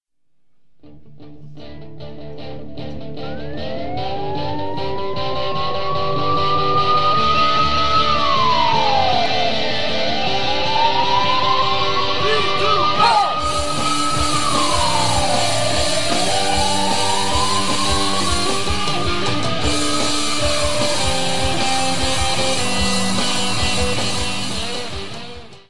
Rock 'n' Roll!!!